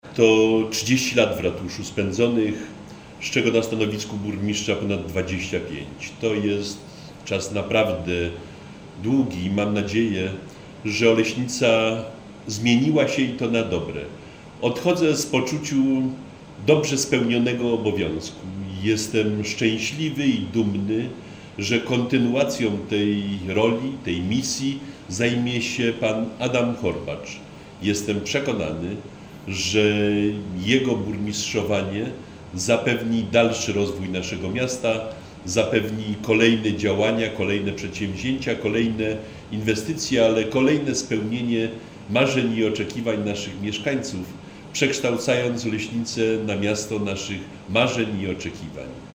– Odchodzę z poczuciem dobrze spełnionego obowiązku – mówi Jan Bronś, były burmistrz Oleśnicy.